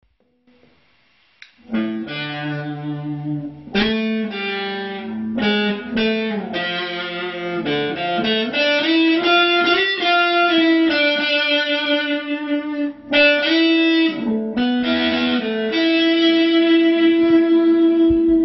First cover
It's catchy.